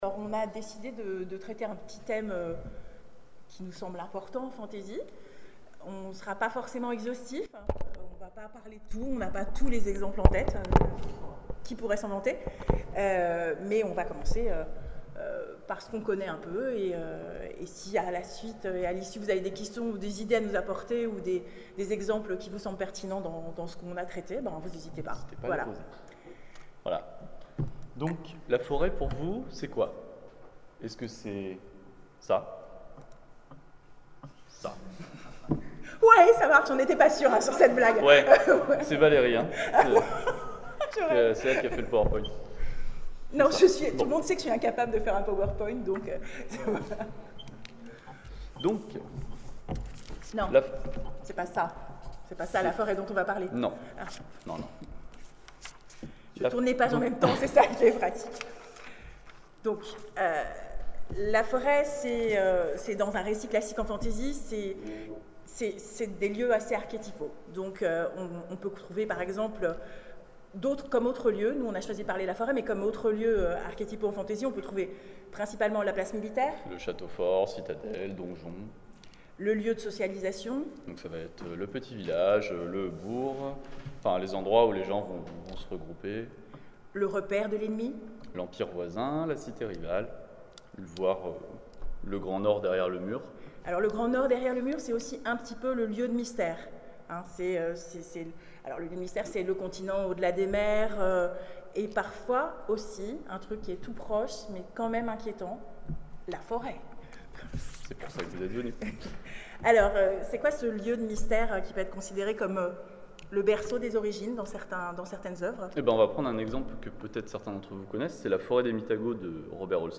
Convention 2012 : Conférence Les Forêts en Fantasy